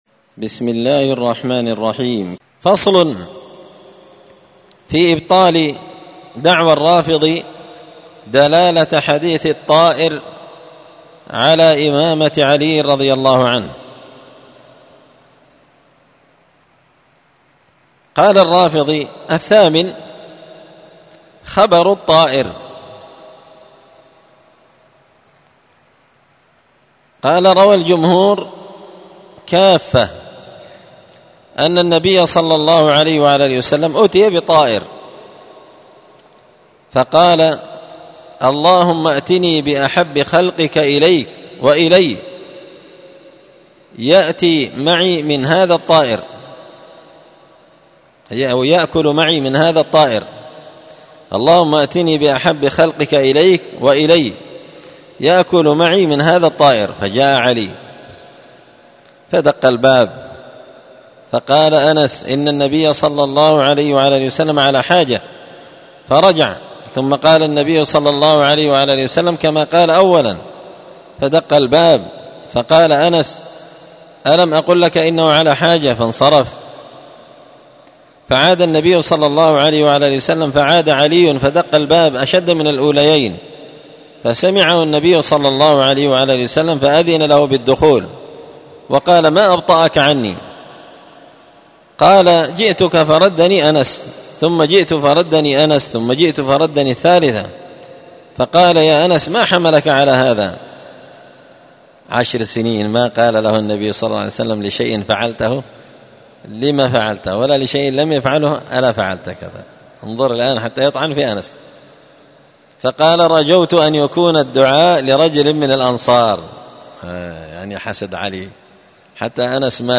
الدرس الثامن والتسعون بعد المائة (198) فصل في إبطال دعوى الرافضي دلالة حديث الطائر على إمامة علي
مسجد الفرقان قشن_المهرة_اليمن